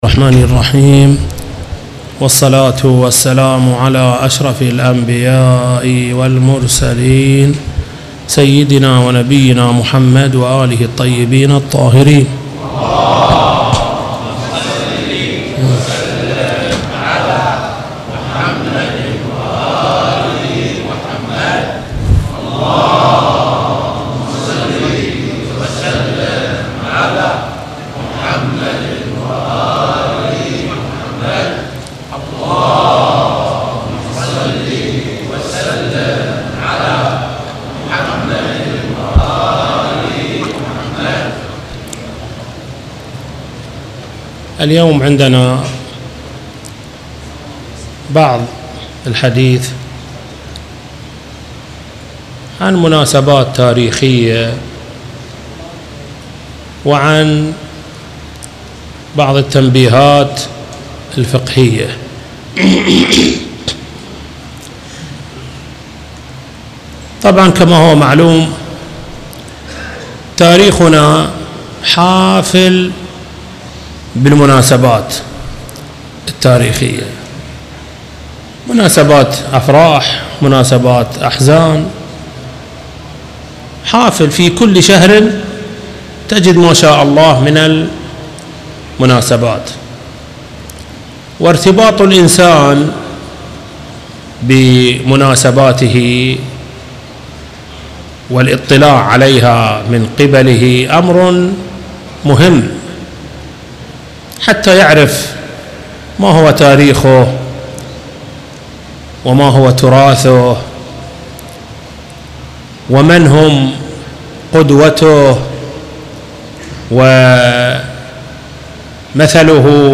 خطبه-الجمعه-مسجد-الامام-الصادق-عليه-السلام-.mp3